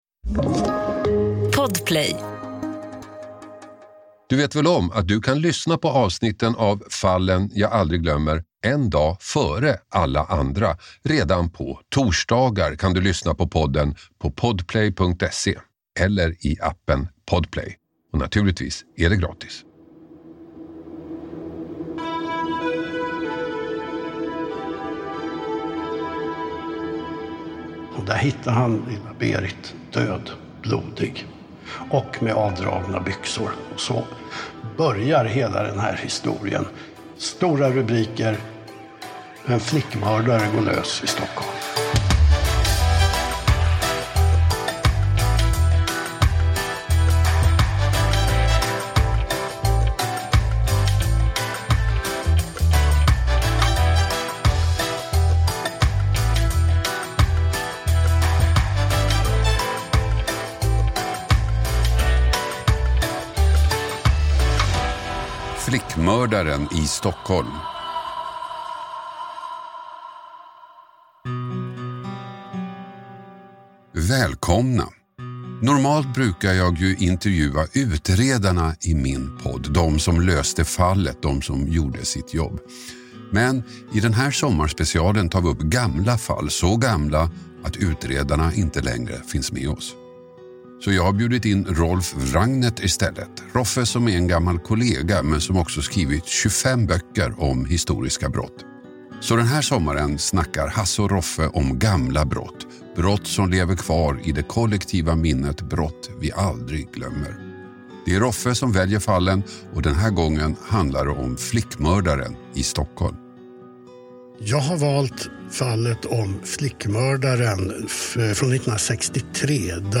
Hasse Aro intervjuar